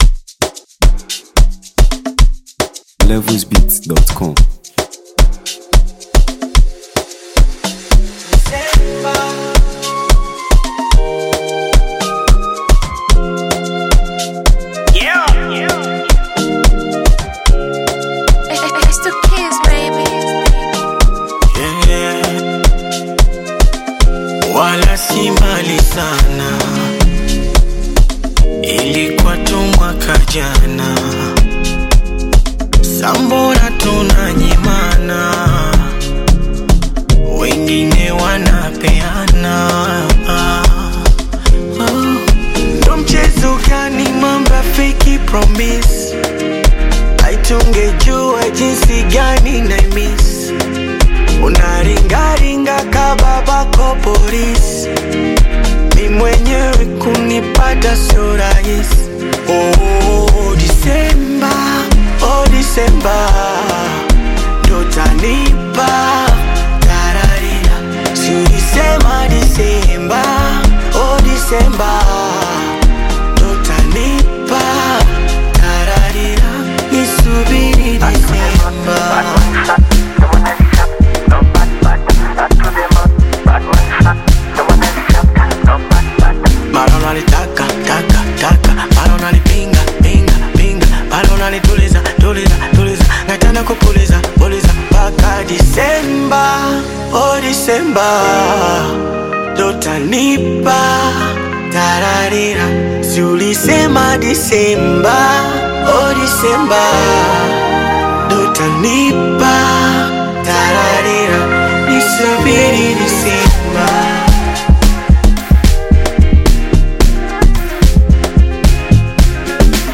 Tanzania Music 2025 3:55